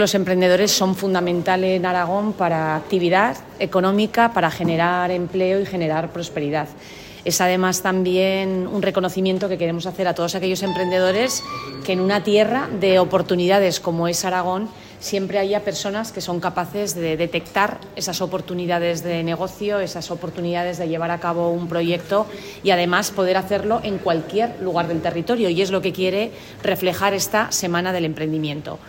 La vicepresidenta del Gobierno de Aragón ha clausurado el acto de presentación de la Semana del Emprendimiento 2024
La vicepresidenta, Mar Vaquero, ha participado en la jornada
La vicepresidenta, Mar Vaquero, ha incidido en la importancia del emprendimiento en la comunidad